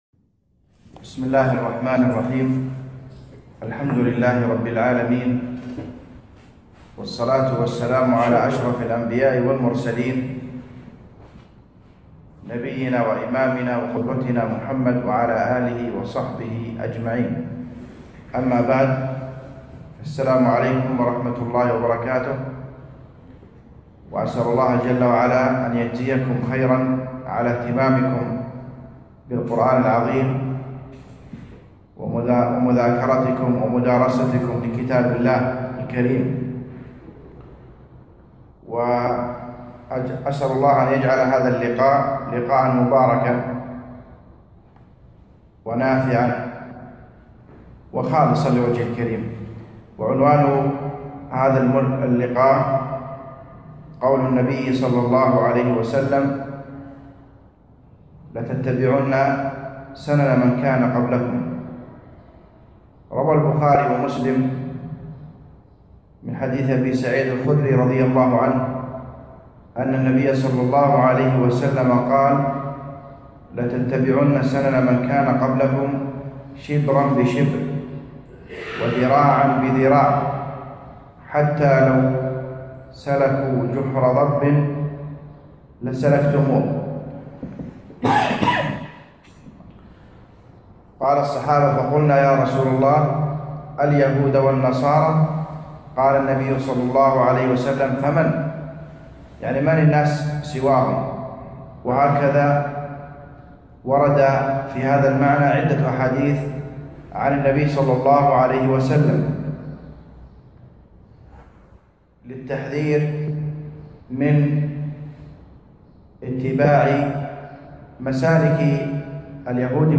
يوم الأربعاء 28 جمادى الأخر 1437 الموافق 6 4 2016 في مركز القرين لدار القرآن نساء/مسائي القصور